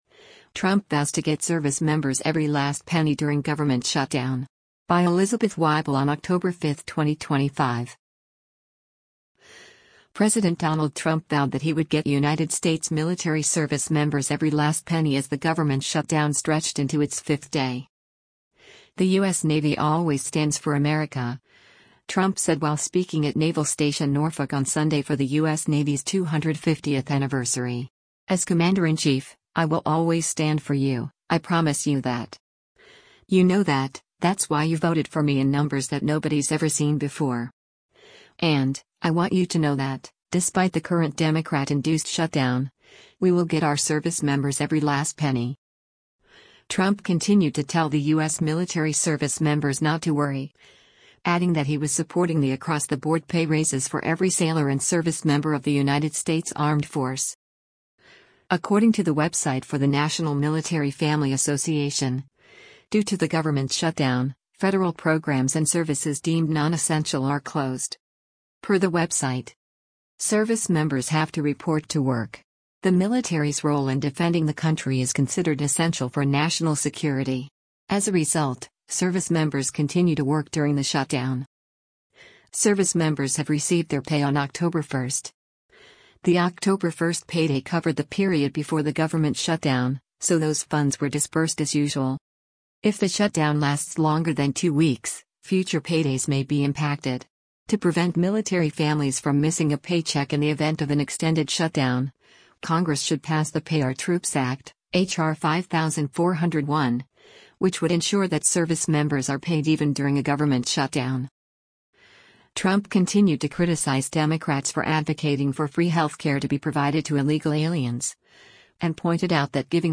“The U.S. Navy always stands for America,” Trump said while speaking at Naval Station Norfolk on Sunday for the U.S. Navy’s 250th anniversary.